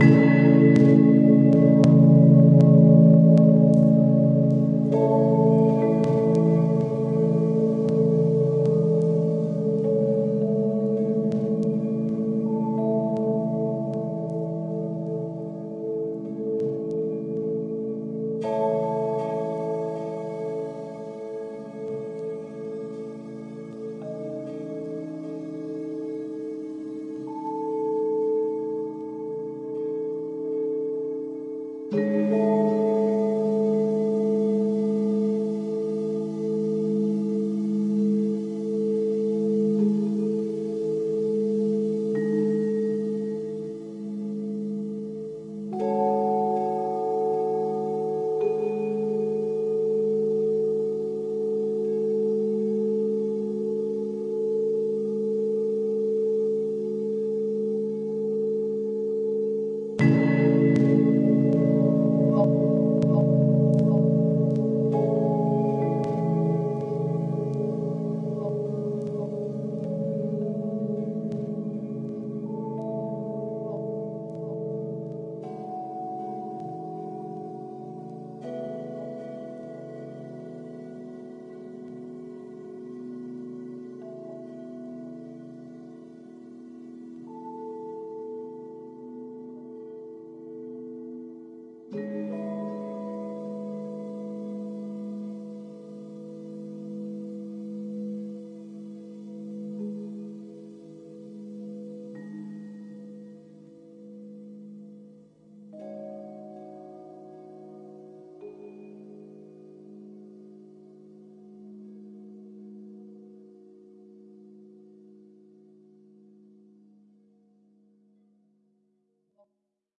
还有我在联合车站录制的源轨道音频
实验
钢琴 音景
口述文字
声道立体声